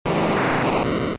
Cri d'Arbok K.O. dans Pokémon Diamant et Perle.